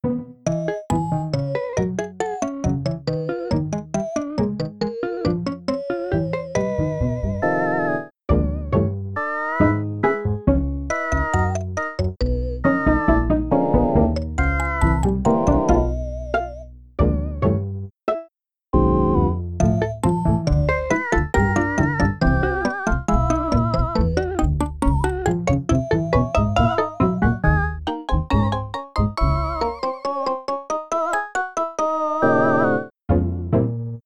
EXAMPLE 3: C700 (no echo or sample preemphasis):
In this example, echo delay was disabled and preemphasis was turned off, to showcase the raw sound for comparison. Even without preemphasis the sound is substantially cleaner and brighter.